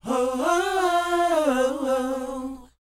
WHOA C C.wav